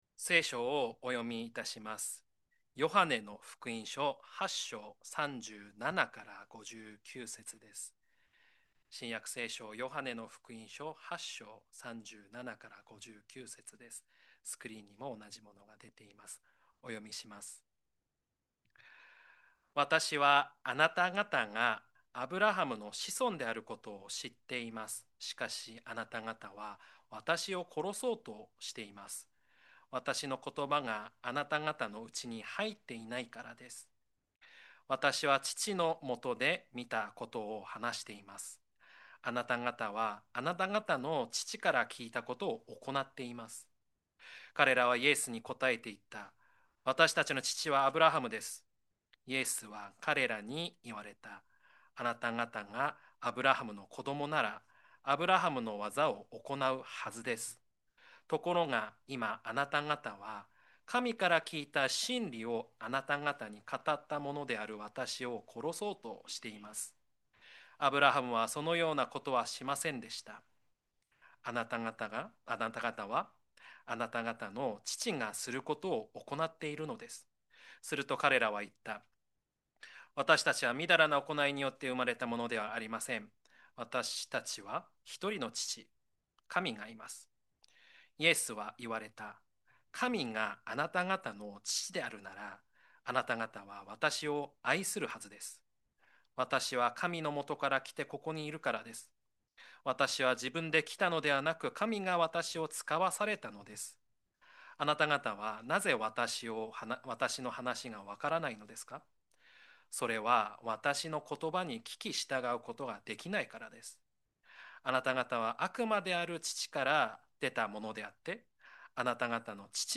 聖書の話 Sermon